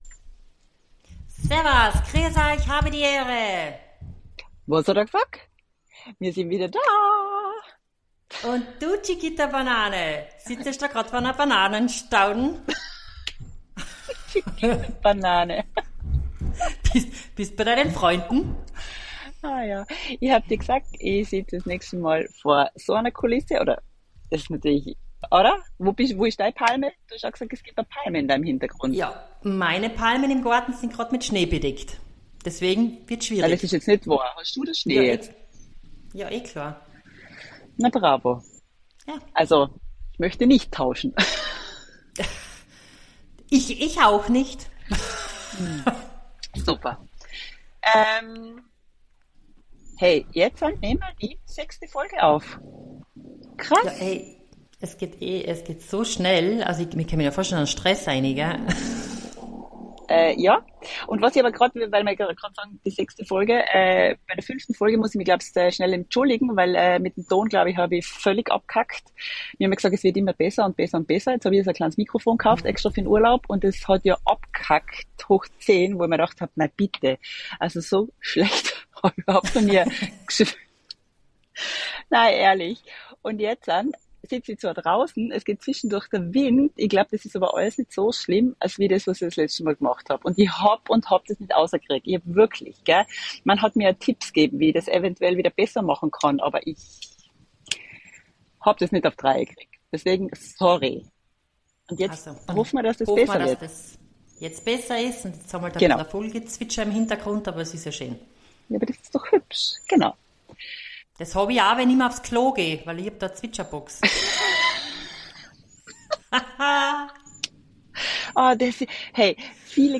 2 Schwestern aus Österreich die seit über 20 Jahren in der Schweiz leben Zwischen Flügen, St.Moritz und eigenem Wasser Es wird wieder interessant und lustig. Die Qualität ist auch wieder ok und der Podcast lässt euch sicher wieder schmunzeln kurze Videos auf Insta „wos hodda gsogt“